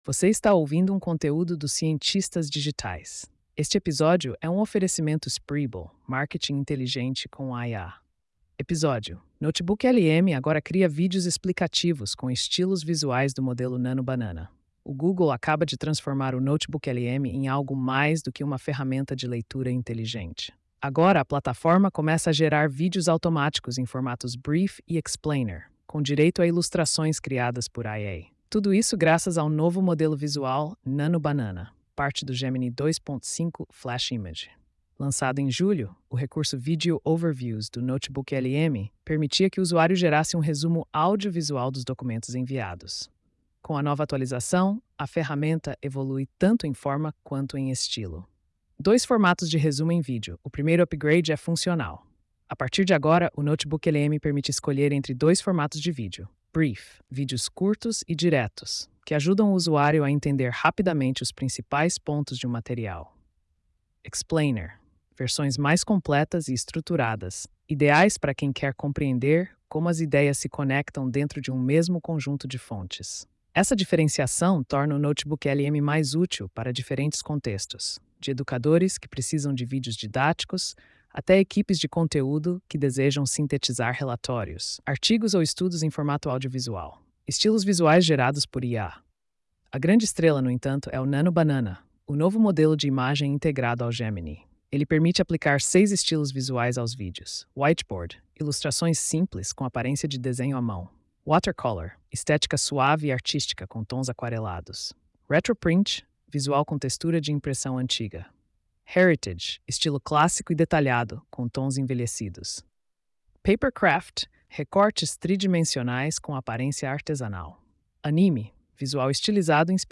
post-4466-tts.mp3